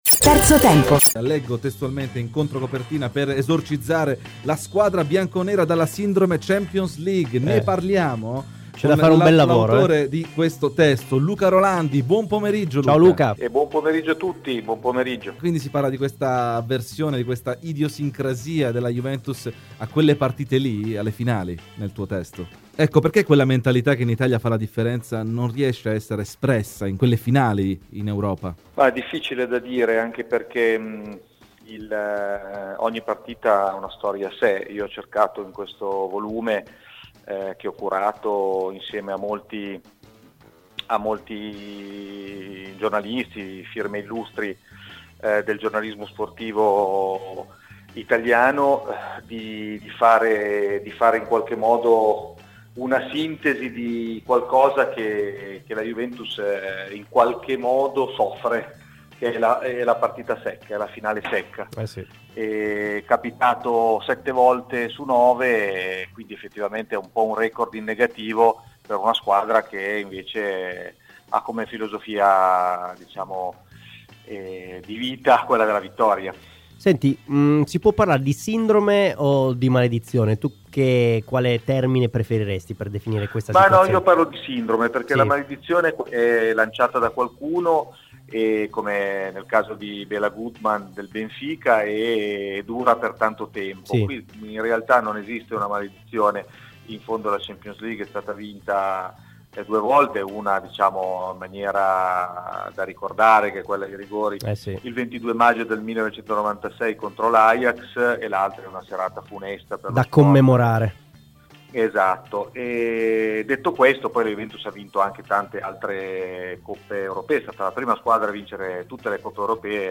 Amauri, ex attaccante di Juventus e Torino, è intervenuto a Radio BiancoNera nel corso di Terzo Tempo. Dal derby della Mole alla nuova Juve, sono tanti i temi toccati dall'italo brasiliano: